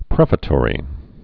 (prĕfə-tôrē)